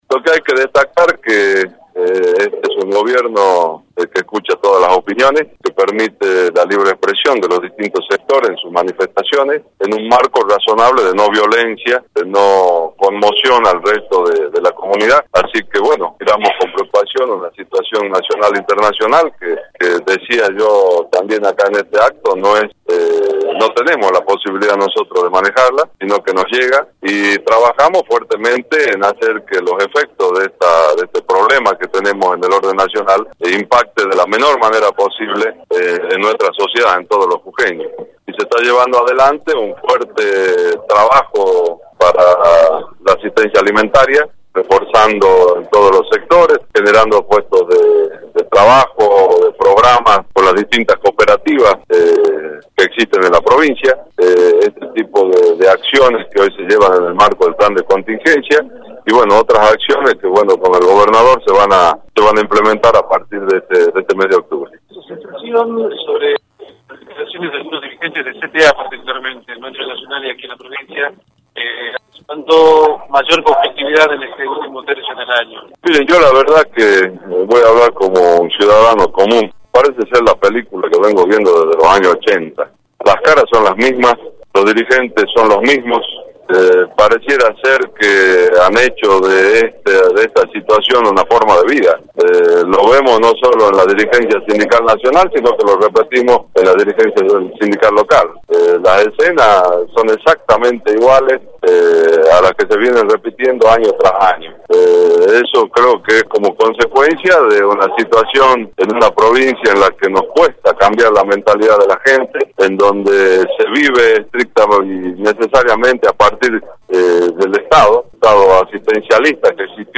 El vicegobernador Carlos Haquim, a cargo de la Gobernación, mantuvo un encuentro con la prensa en el que fue consultado por la medida de fuerza nacional convocada por la CGT y la CTA el martes último y su desarrollo en la provincia.
26-CPN-CARLOS-HAQUIM-Vicegobernador-de-la-Provincia.mp3